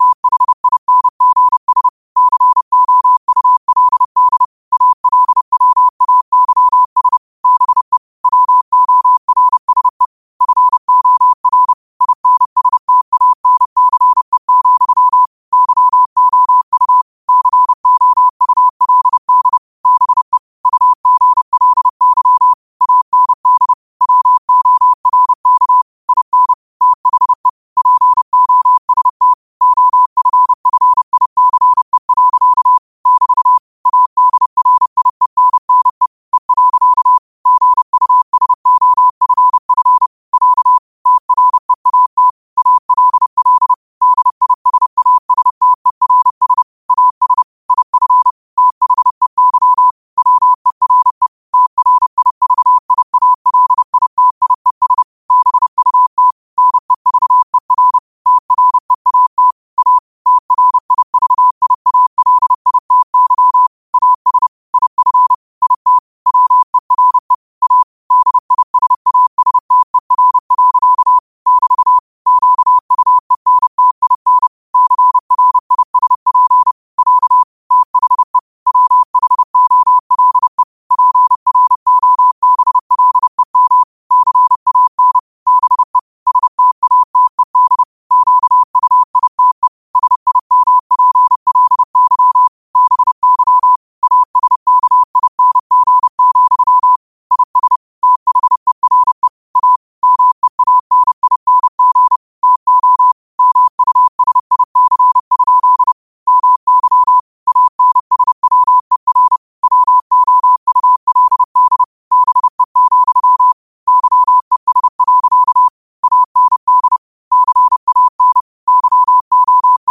30 WPM morse code quotes for Sat, 07 Mar 2026
Quotes for Sat, 07 Mar 2026 in Morse Code at 30 words per minute.